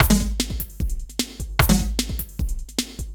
CRATE V DRUM 1.wav